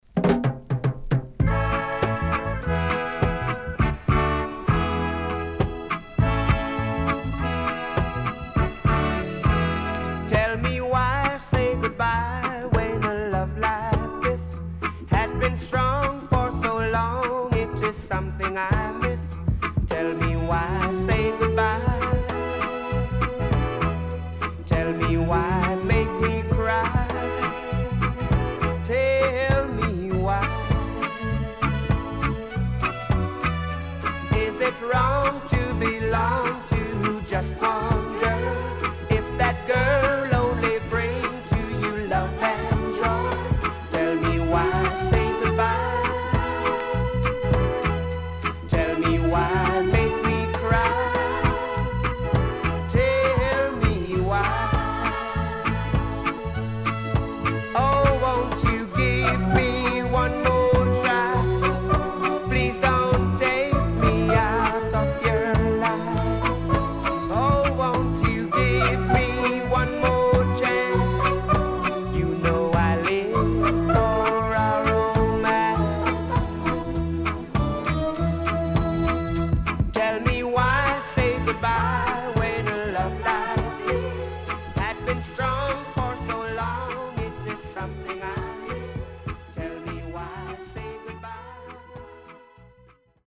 HOME > REGGAE / ROOTS
CONDITION SIDE A:VG+
SIDE A:少しチリノイズ、プチノイズ入ります。